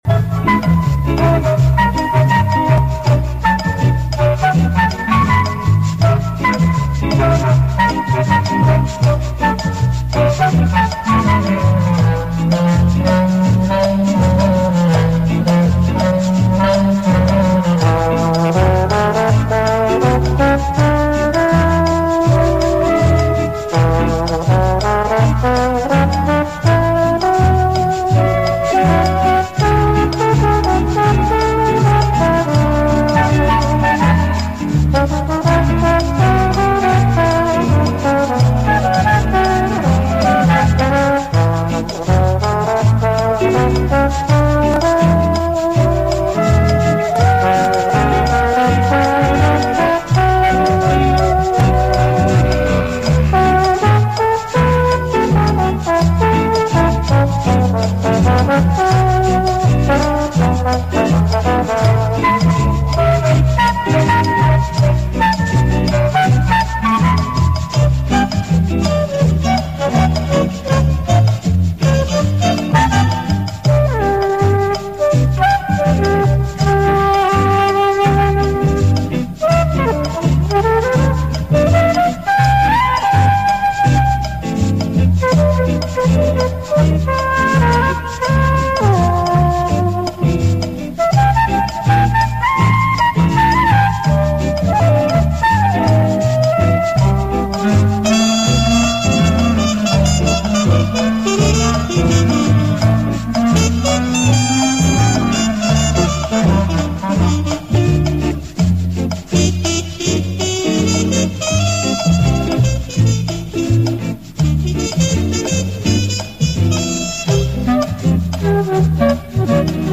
Какой оркестр исполняет эту мелодию и как называется данная композиция. Часто звучала на Маяке в 74-75 годах в эстрадных оркестровых программах. По-моему это оркестровая обработка какой-то популярной песни